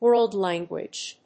アクセントwórld lánguage